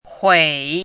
“虺”读音
huǐ
虺字注音：ㄏㄨㄟˇ
huǐ.mp3